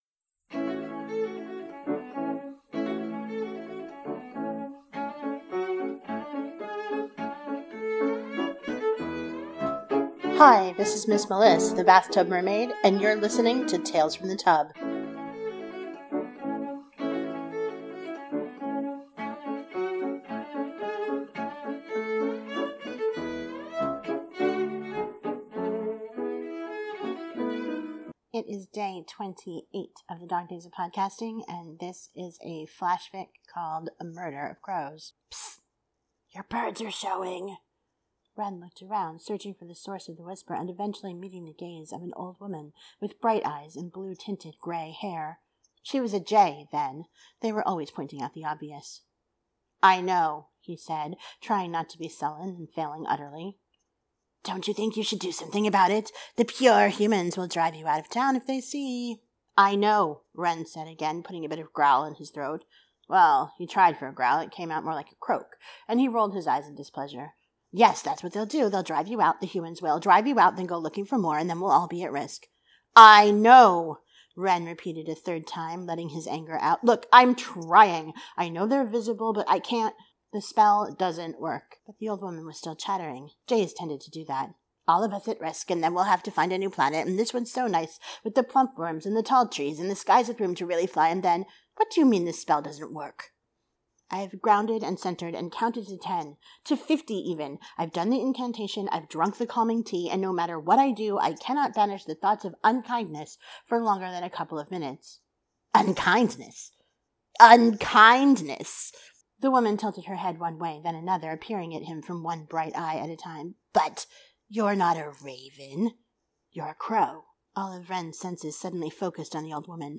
Flash-fiction. Ravens and Crows aren’t quite the same bird.
• Music used for the opening and closing is David Popper’s “Village Song” as performed by Cello Journey.